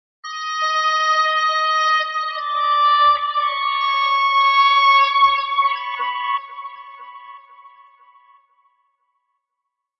描述：音乐盒的音乐铃声。
标签： 铃声 音乐 八音盒